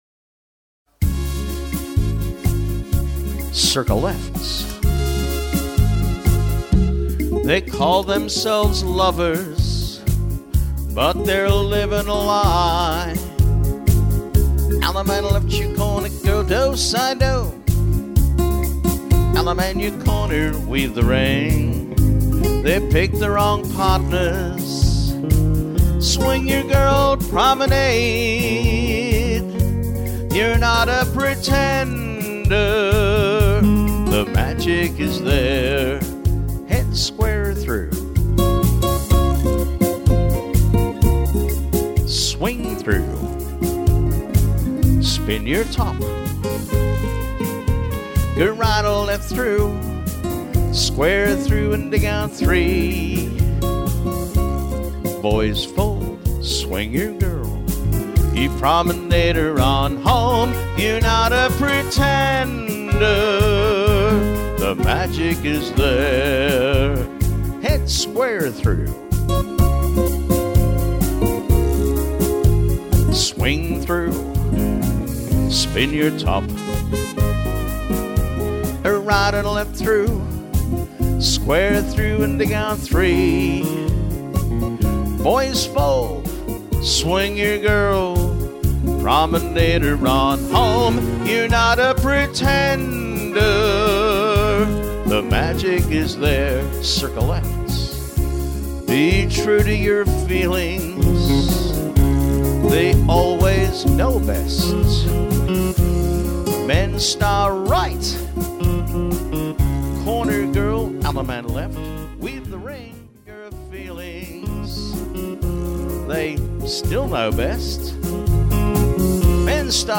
Vocal Tracks
Mainstream